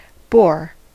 Ääntäminen
US
IPA : /bɔːɹ/